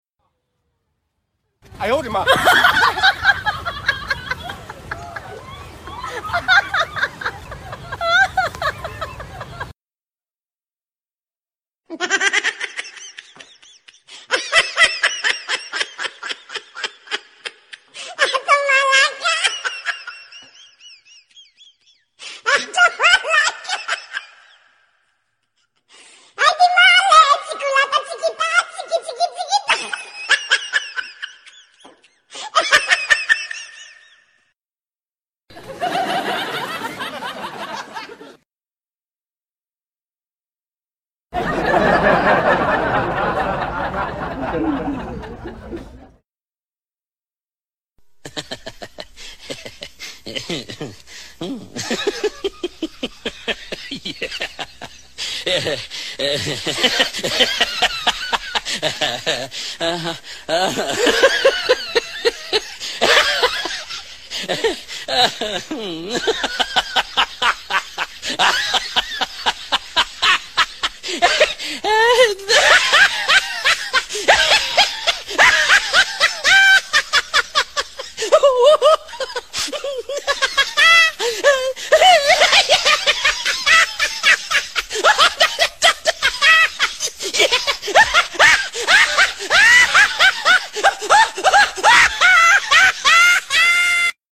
Tổng hợp Tiếng Cười Hay